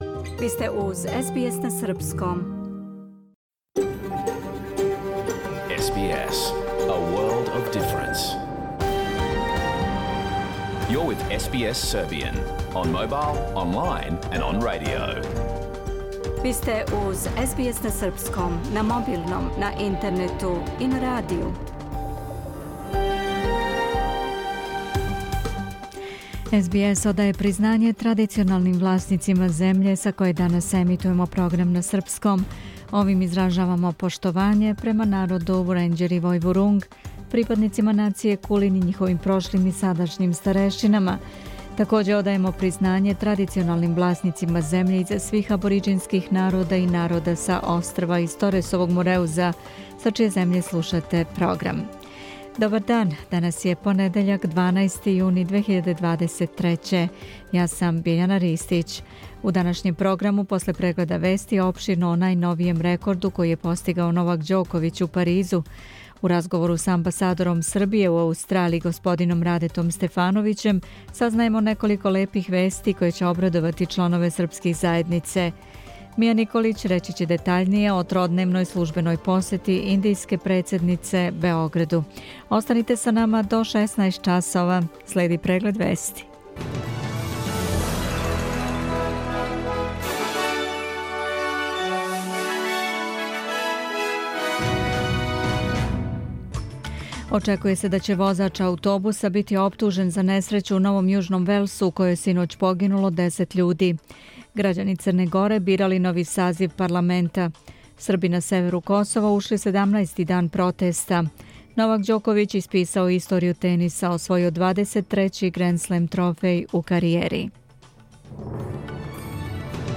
Програм емитован уживо 12. јуна 2023. године